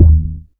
KICK.43.NEPT.wav